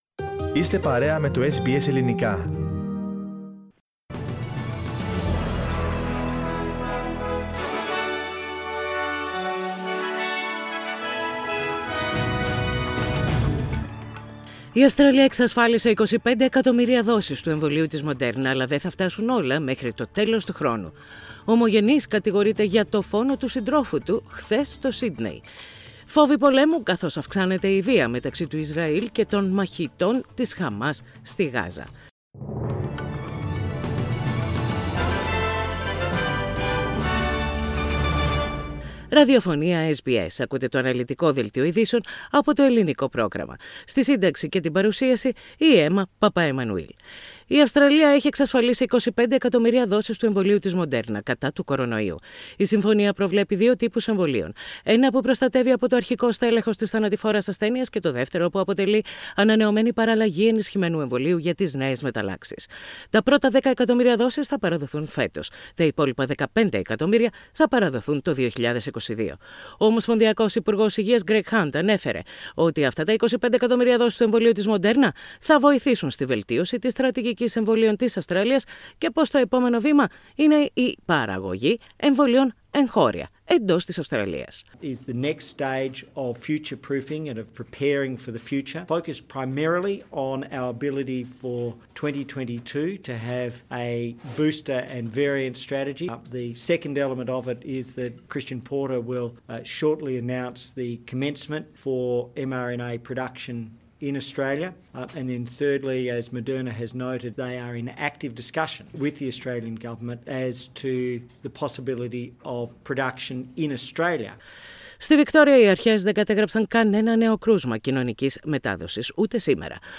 Ειδήσεις στα Ελληνικά - Πέμπτη 13.5.21
Οι κυριότερες ειδήσεις της ημέρας από το Ελληνικό πρόγραμμα της ραδιοφωνίας SBS.